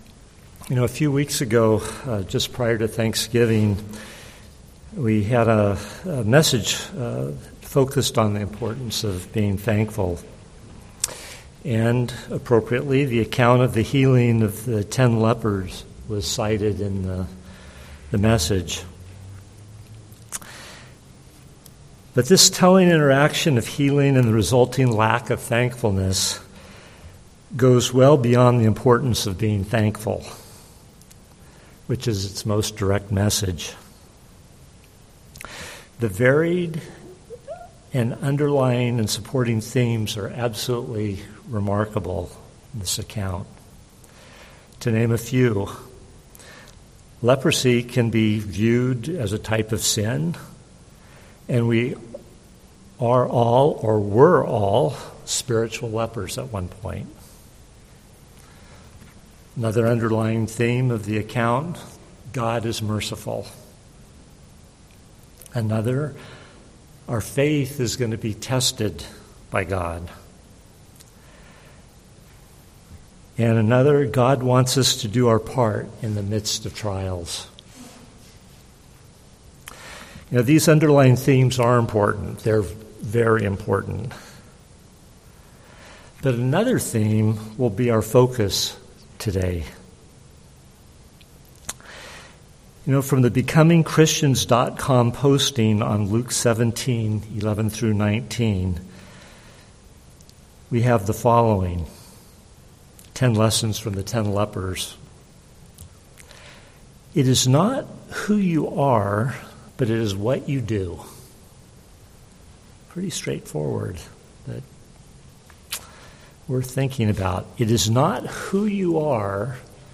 Sermons
Given in San Jose, CA